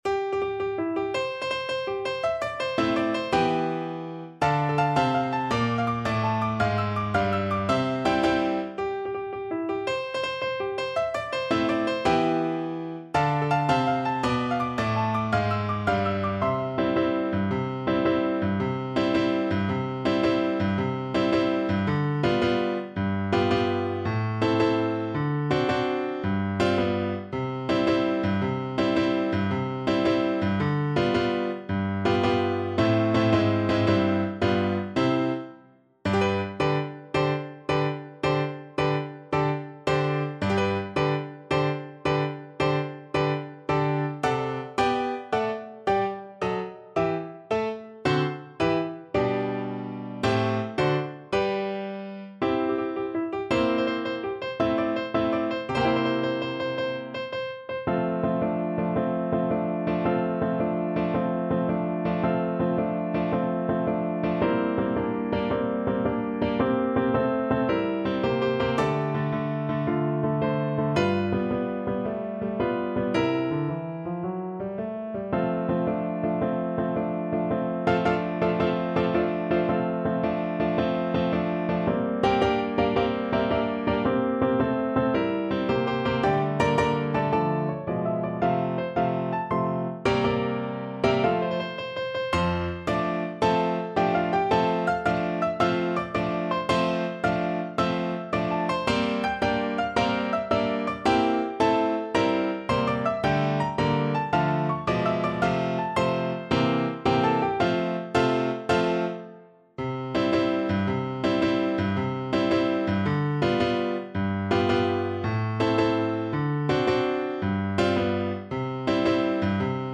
6/8 (View more 6/8 Music)
March .=c.110